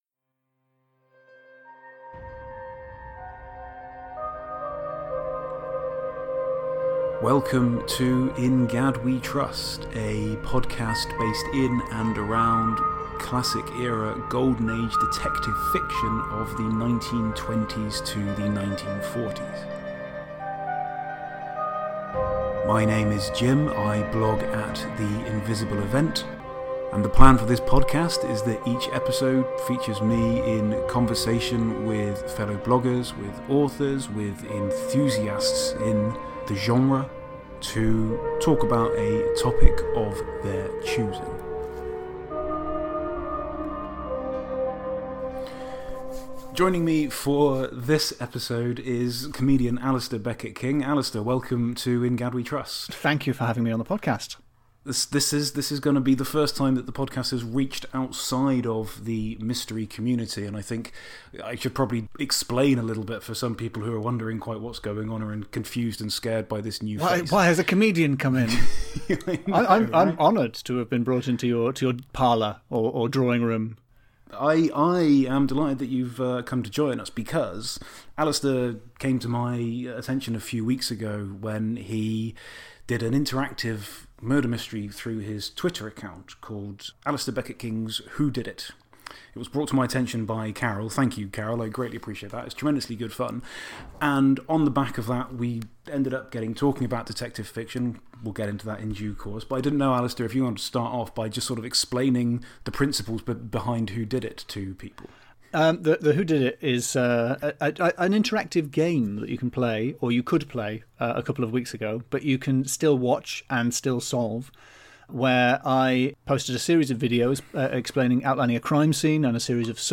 Another really entertaining episode with a welcome dose of laughter.